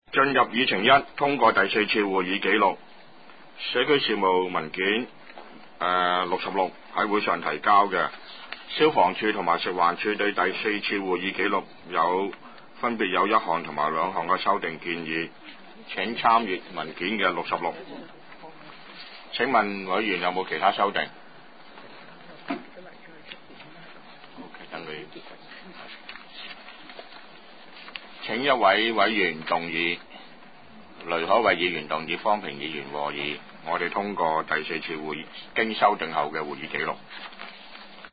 第五次會議(一零/一一)
葵青民政事務處會議室